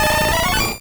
Cri d'Aéromite dans Pokémon Rouge et Bleu.